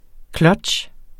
Udtale [ ˈklʌdɕ ]